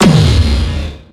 railgun-turret-gunshot-3.ogg